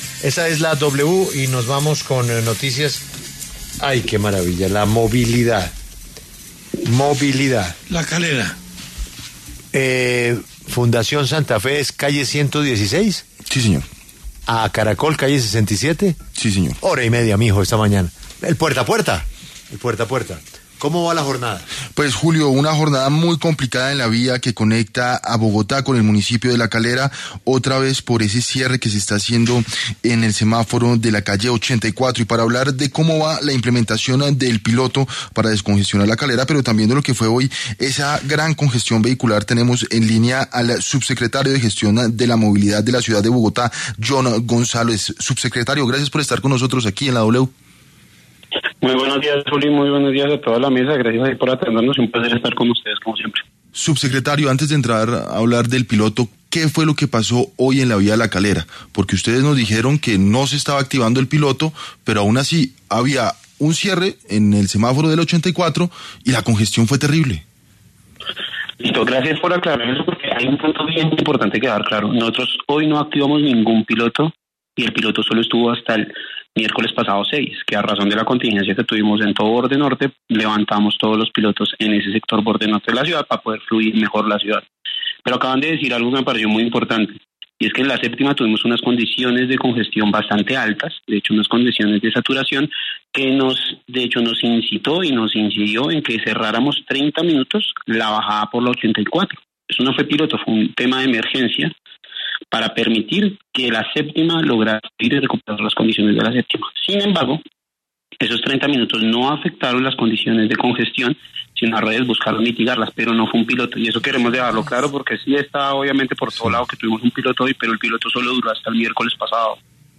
El subsecretario de movilidad de Bogotá, John González, aclaró en entrevista con La W que la situación no se debió a la implementación de un piloto, sino a un cierre temporal en el semáforo de la calle 84, medida que buscaba aliviar la carga en la avenida Séptima.